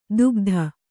♪ dugdha